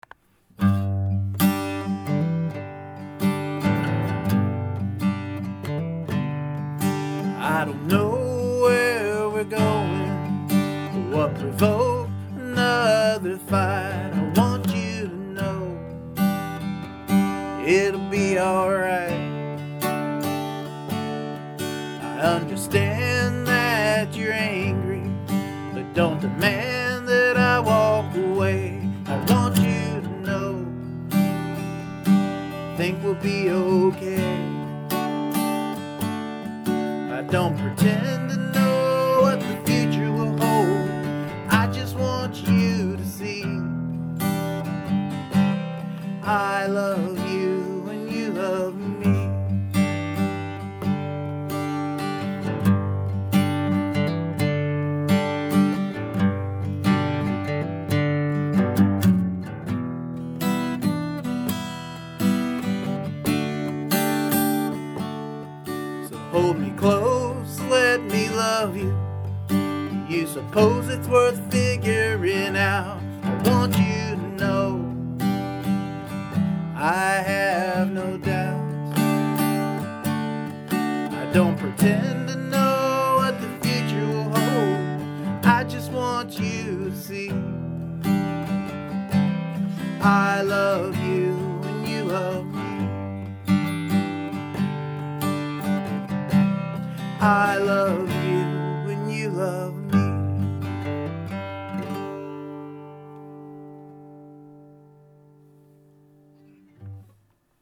Live in the Living Room.. erm, Basement - A pretty quick one this morning for the Future Festival
Sweet little tune here brother.
Man and guitar, what a nice combo! Melodic folky feel here.
Live in the living room, fresh and vital, love it to bits, i haven't said this for a while.
Great vocals and really tight guitar playing.